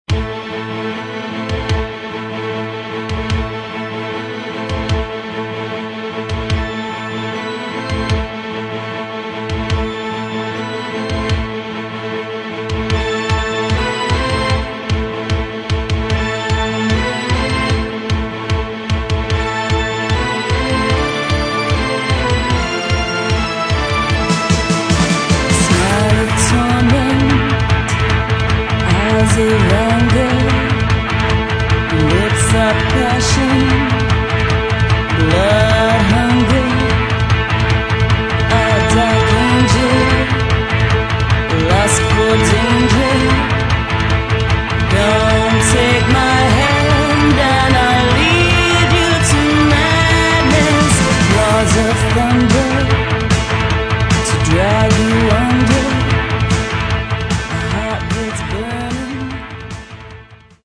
Рок
демонстрирует более мрачную и задумчивую сторону группы
вокал, флейта
гитара, программинг, композиция, вокал